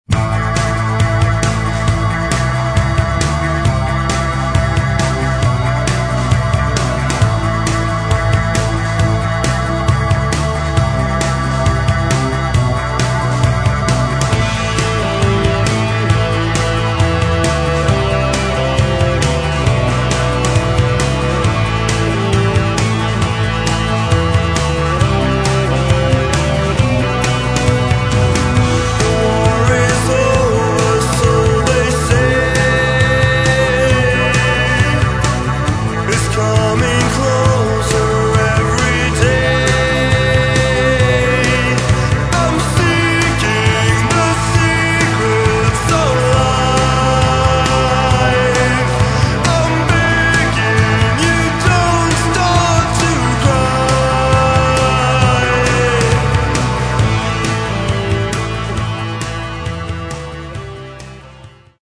Второй полноформатный альбом шведской готической группы.
вокал, акустическая гитара
гитара
бас
клавиши
барабаны
гармоника
женский вокал